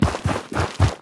biter-walk-big-9.ogg